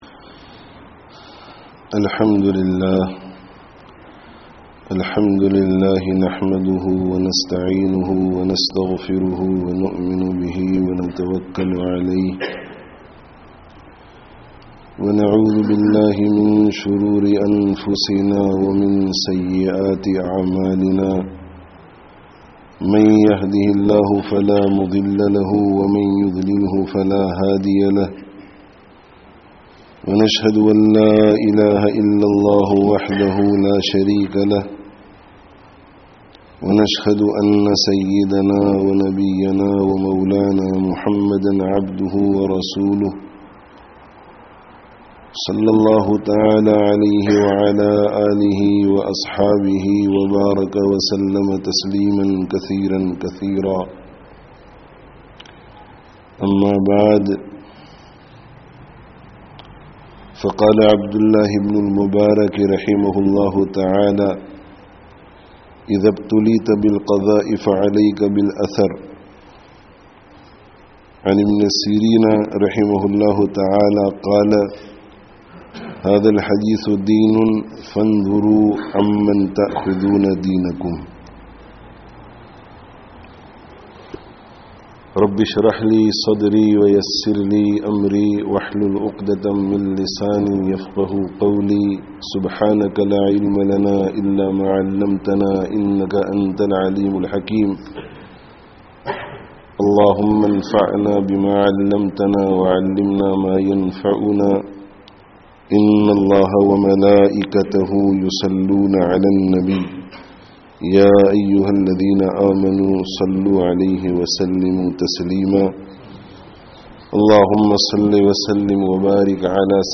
Tablīgh, 'Ilm awr Tazkiyah (Madinah Masjid, Limbe, Malawi 14/08/18)